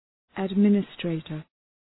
Shkrimi fonetik{æd’mını,streıtər}